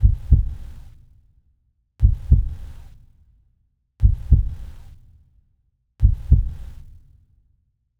Hearbeat.wav